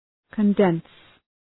Προφορά
{kən’dens}